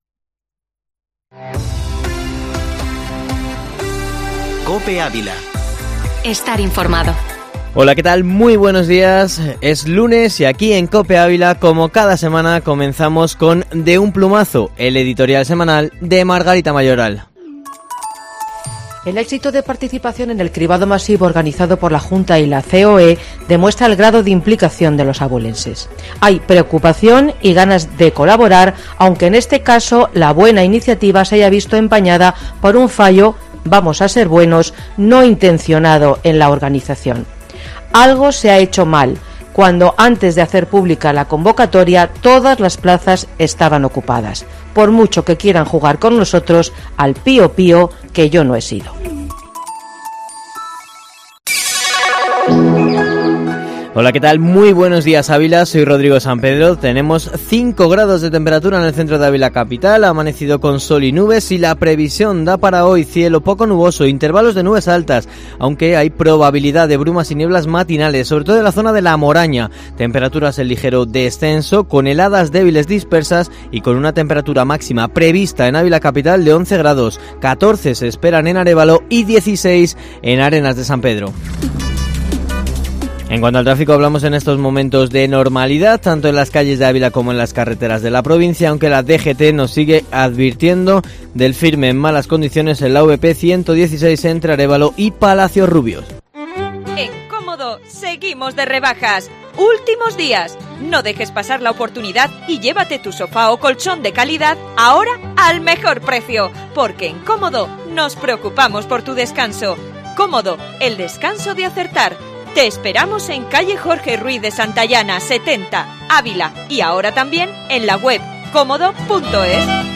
Informativo matinal Herrera en COPE Ávila 15/02/2021